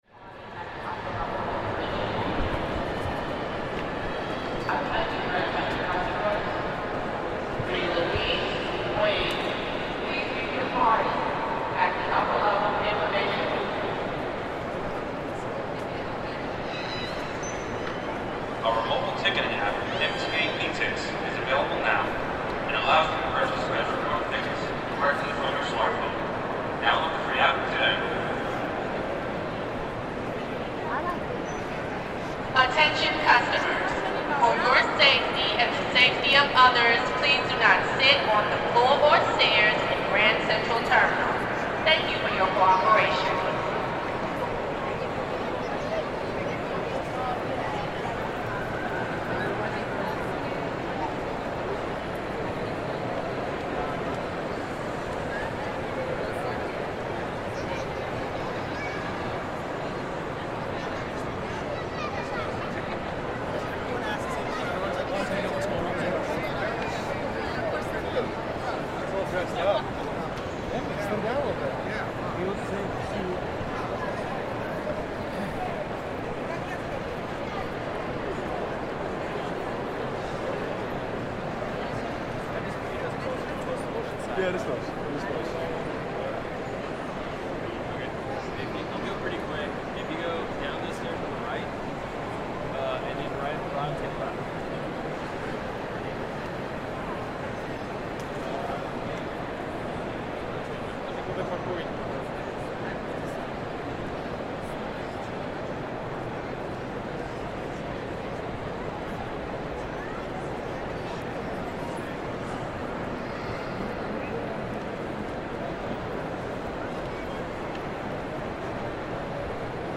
Grand Central Terminal is an incredible space - visually and in terms of space. Its huge ceilings and marbled hard surfaces lend it an amazing reverb, and as an extremely busy rail terminal, it's almost always full of people rushing to their next destination, so there's a constant reverb-drenched hum in the space at all times, interspersed with sharp interjections from the PA announcements, which cut through the hubbub.